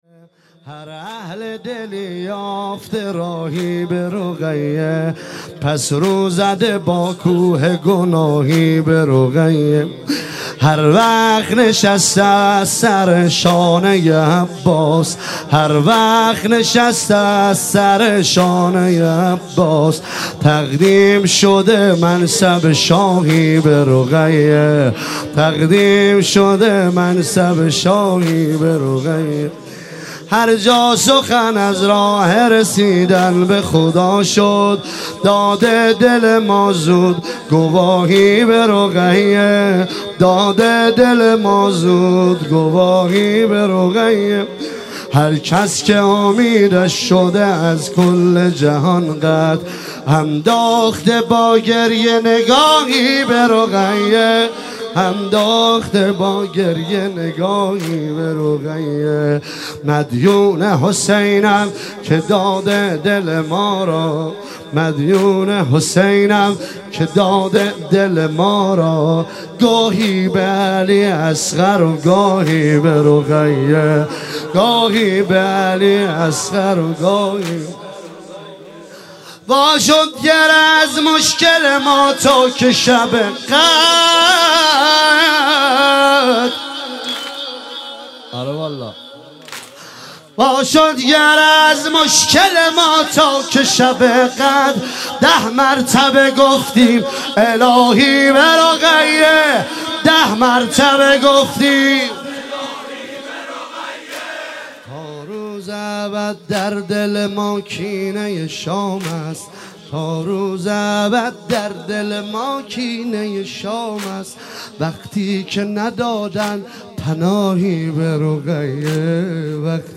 شب سوم محرم 97 - واحد - هر اهل دلی یافته راهی